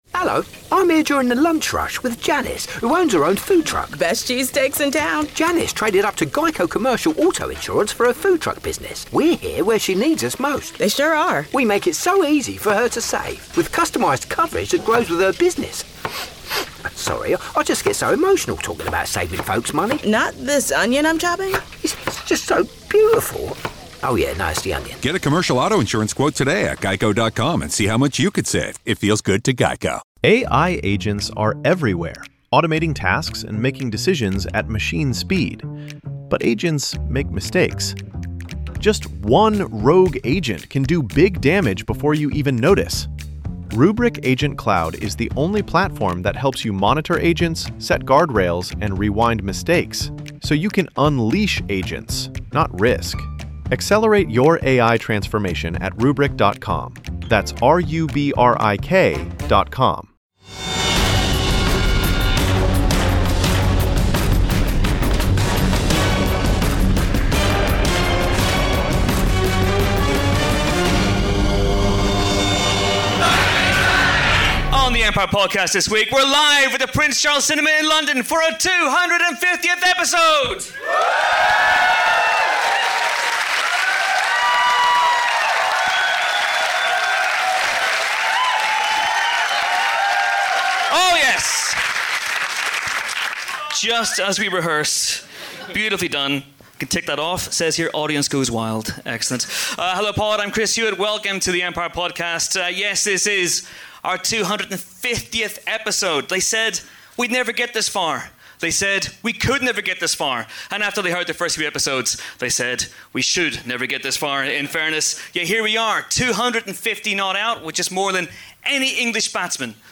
For our milestone 250th episode, we're LIVE at the Prince Charles Cinema in London's Leicester Square, with friend-of-the-pod Chris McQuarrie, a sold-out crowd of pod-fans, and the biggest 'bangily-bang' yet.